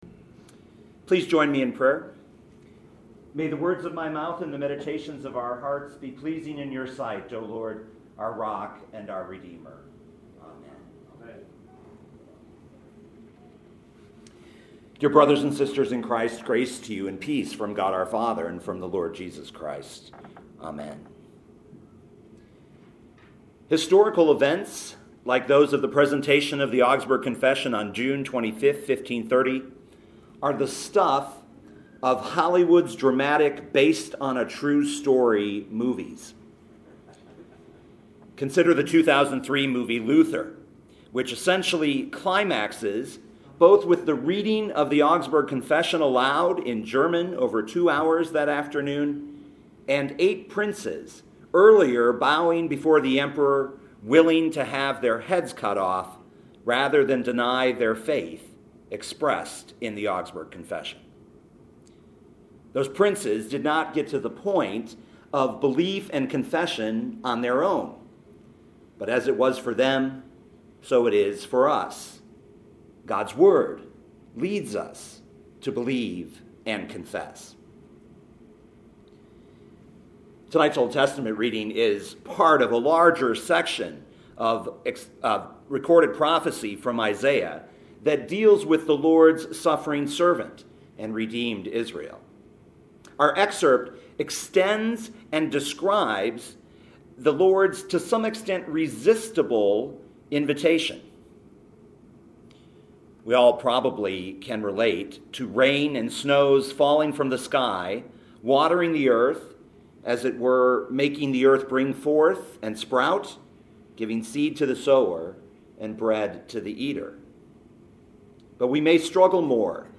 2018 Isaiah 55:6-11 Listen to the sermon with the player below, or, download the audio.